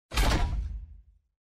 ui_interface_23.wav